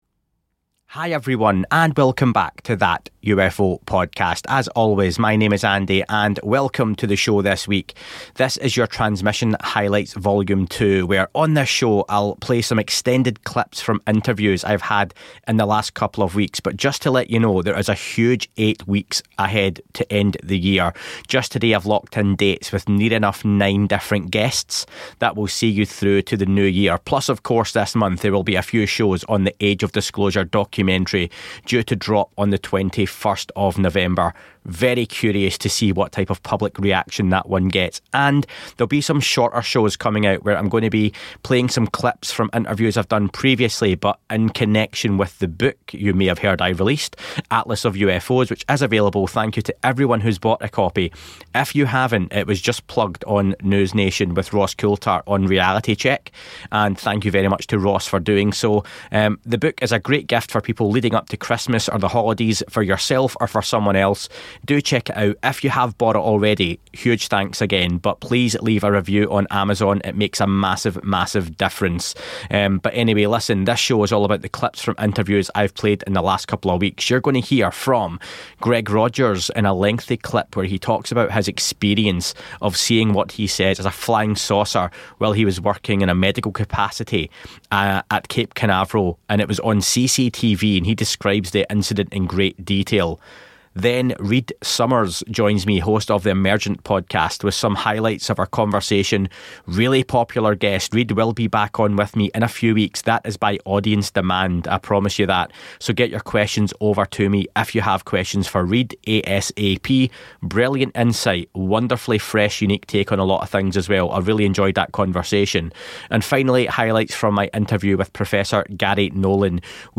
Bringing you up to date news, interviews and guests from around the world of UFOs, UAP & connected phenomena